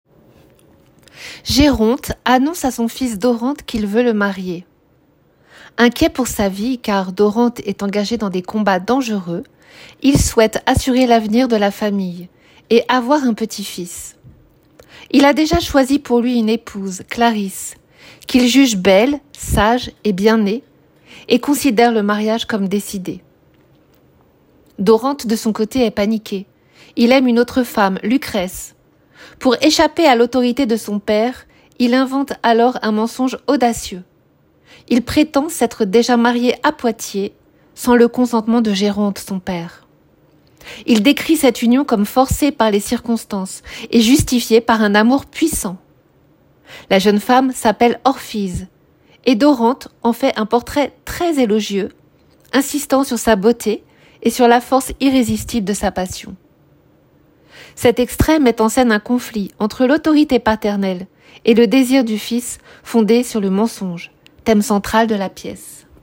À la fin de cette page, tu peux télécharger un fichier audio qui contient le résumé en français. Écoute-le et entraîne-toi à le lire, afin d’avoir la prononciation la plus parfaite possible !